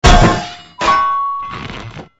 AA_drop_anvil.ogg